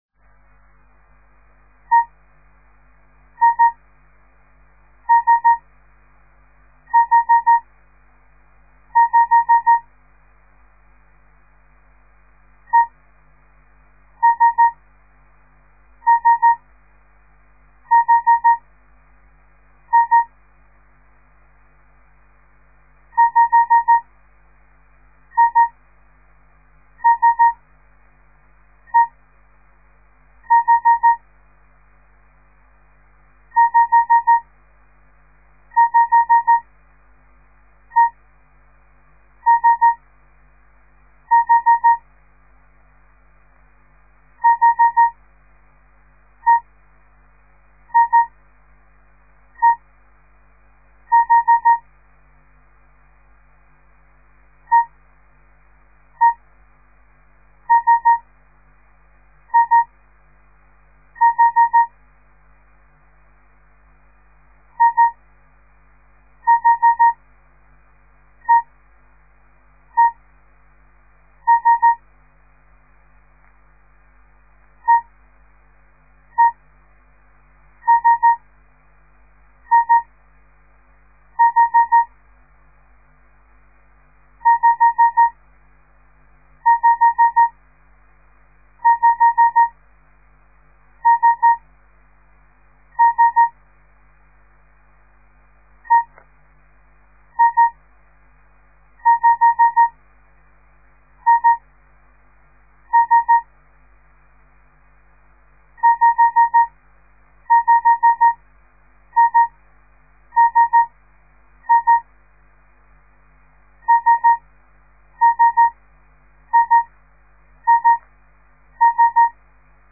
De geluidsfiles bestaan uit geseinde text in letter of cijfergroepen , steeds groepen van 5 tekens en iedere les bestaat uit 25 groepen
De letter E   dit   /  De letter I  di-dit    de letter  S  di-di-dit    / de letter H  di-di-di-dit   en het cijfer  5  di-di-di-di-dit
we beginnen extra langzaam